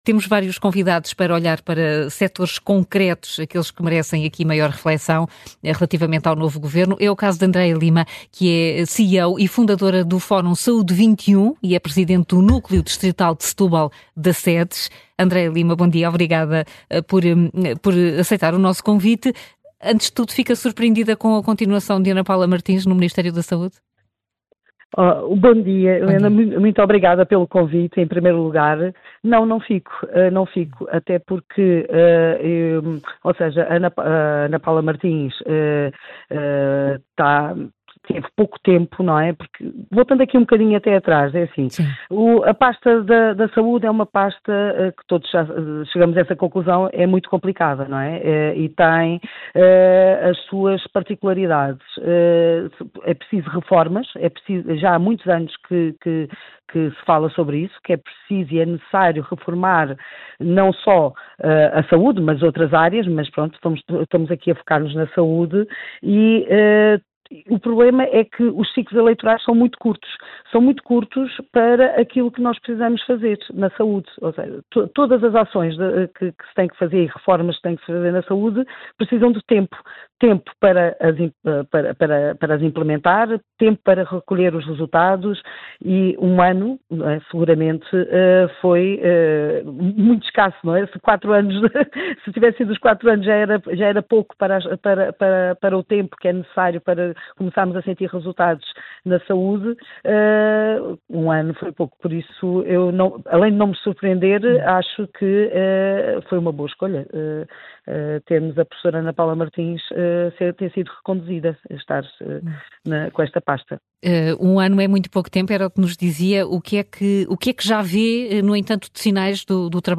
foi convidada do programa “Contra-Corrente” da Rádio Observador